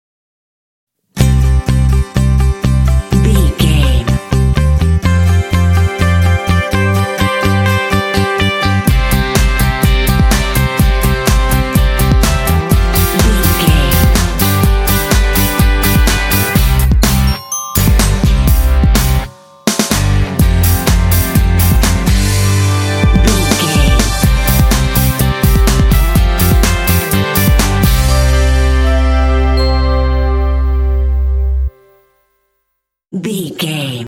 Uplifting
Ionian/Major
bouncy
happy
groovy
drums
electric guitar
bass guitar
synthesiser
pop
alternative rock